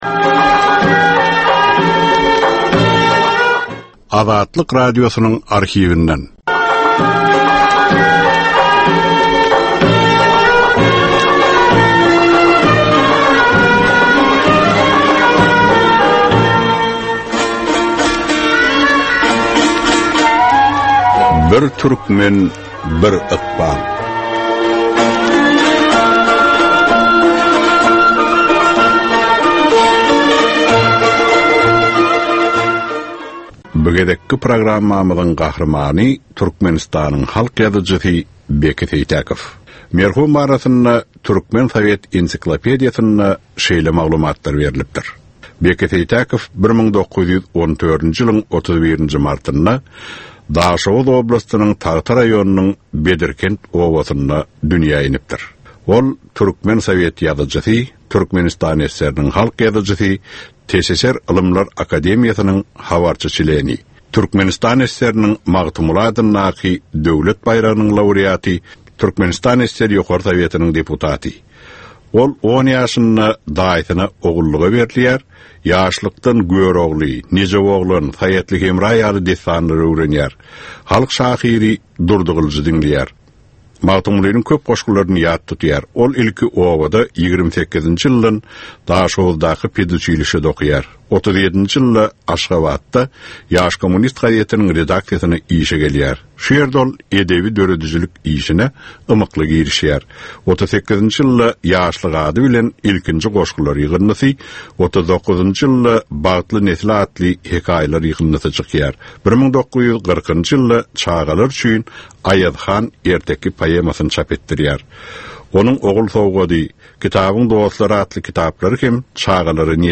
Türkmenistan we türkmen halky bilen ykbaly baglanyşykly görnükli şahsyýetleriň ömri we işi barada 55 minutlyk ýörite gepleşik. Bu gepleşikde gürrüňi edilýän gahrymanyň ömri we işi barada giňişleýin arhiw materiallary, dürli kärdäki adamlaryň, synçylaryň, bilermenleriň pikirleri, ýatlamalary we maglumatlary berilýär.